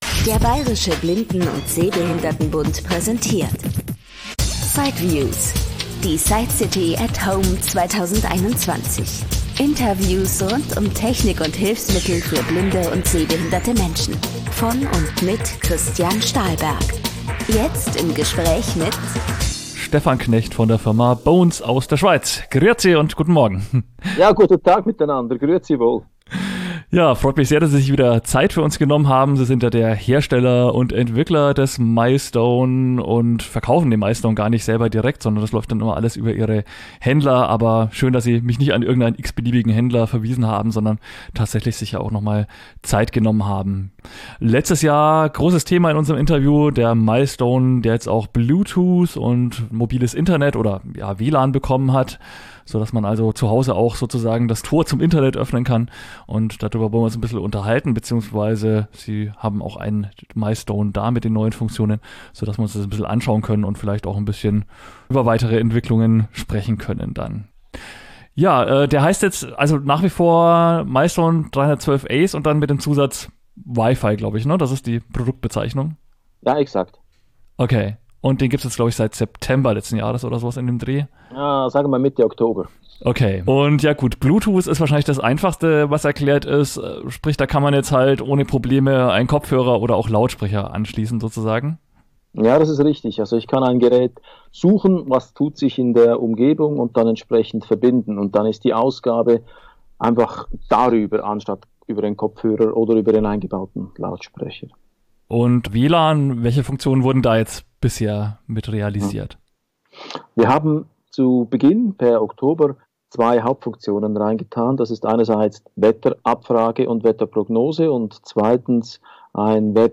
Kern des Podcasts sind Berichte und Interviews von der Sightcity in Frankfurt, der weltweit größten Messe zu diesem Themenfeld.